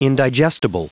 Prononciation du mot indigestible en anglais (fichier audio)
Prononciation du mot : indigestible